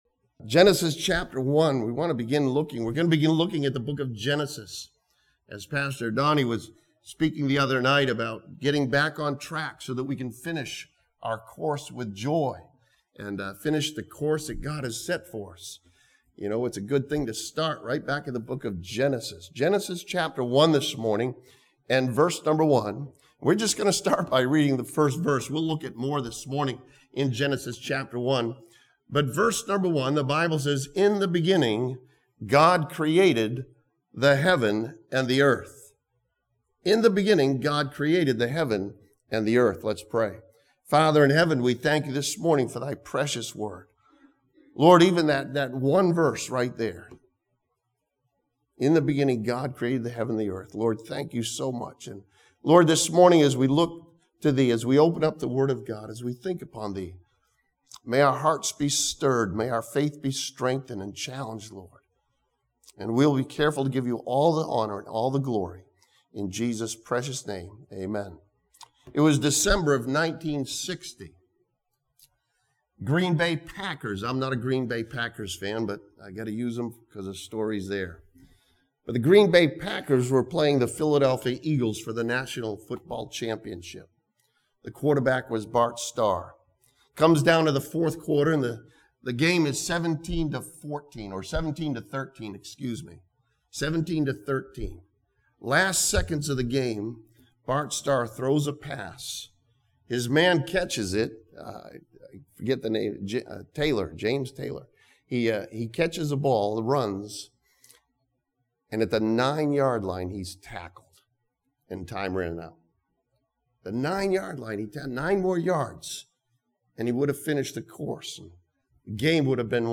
This sermon from Genesis 1 begins a new series by studying the work of God in creation.